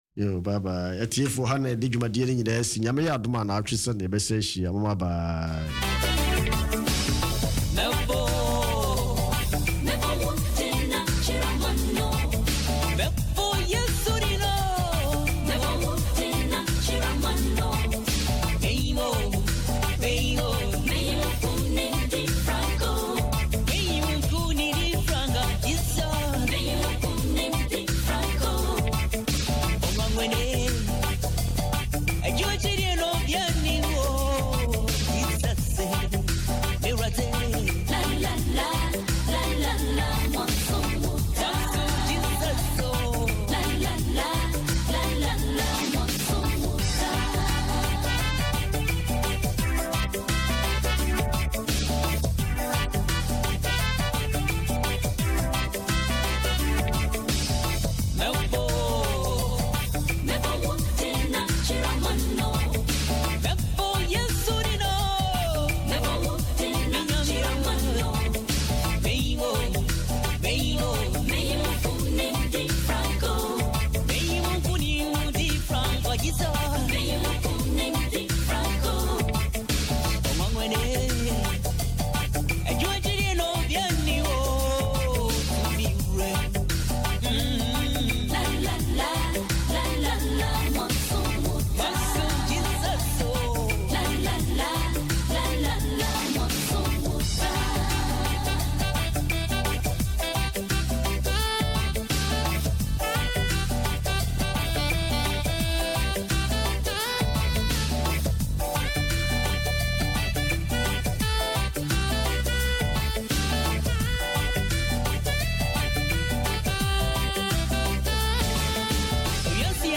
Speciale Kerkdienst (EBG-Zuidoost) - SALTO